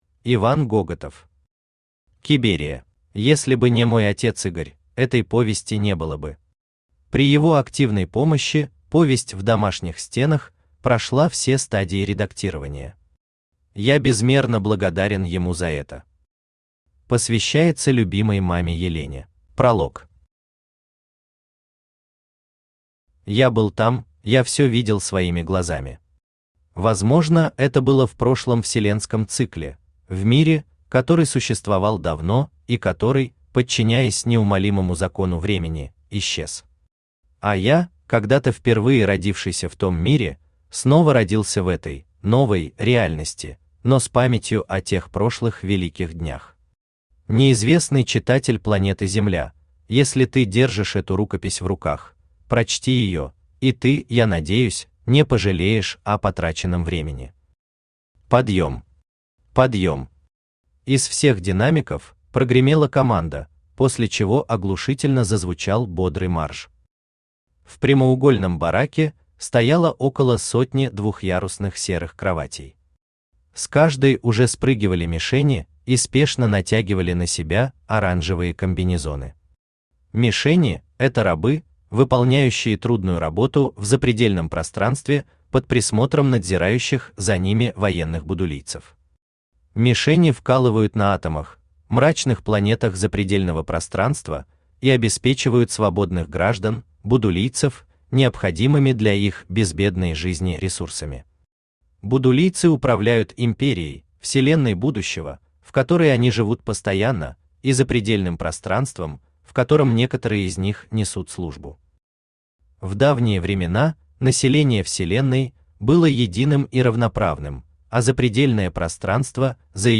Аудиокнига Киберия | Библиотека аудиокниг
Aудиокнига Киберия Автор Иван Гоготов Читает аудиокнигу Авточтец ЛитРес.